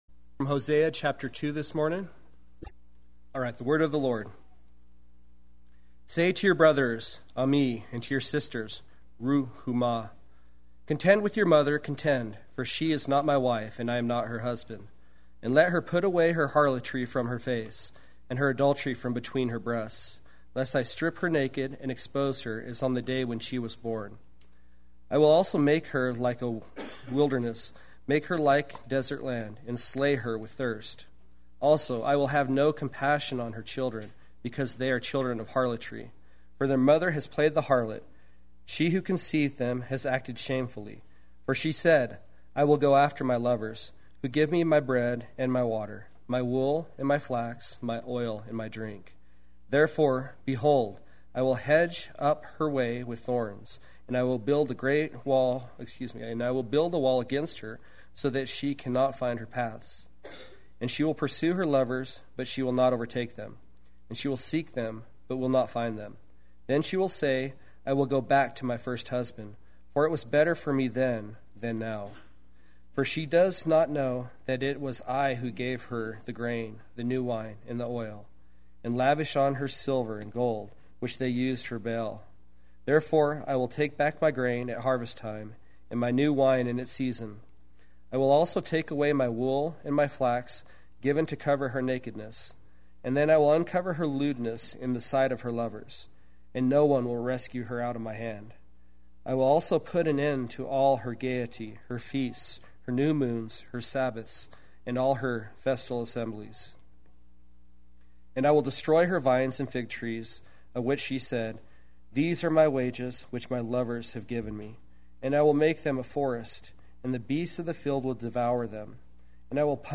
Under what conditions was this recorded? I Will….They Will Sunday Worship